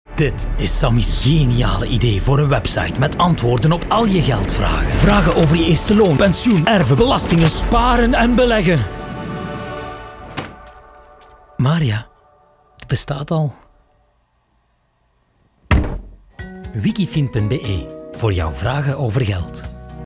Accessible, Friendly, Soft, Young, Versatile
Commercial